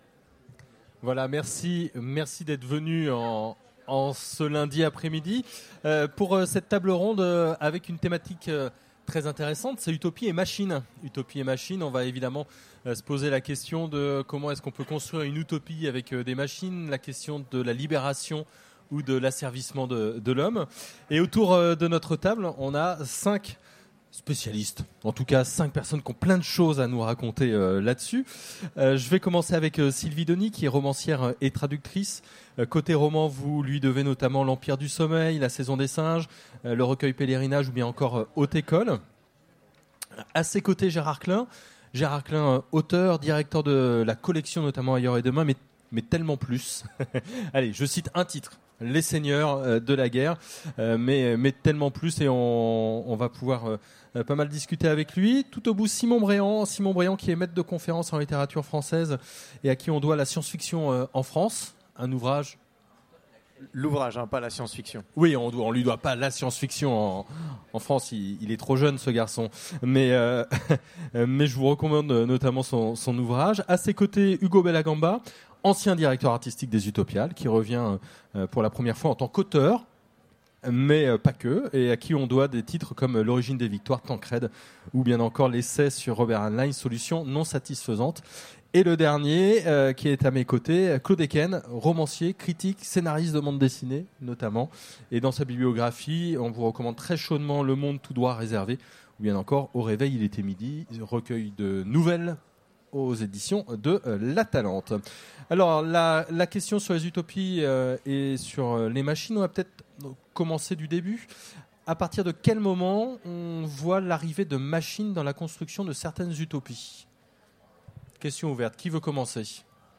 Utopiales 2016 : Conférence Utopie et machines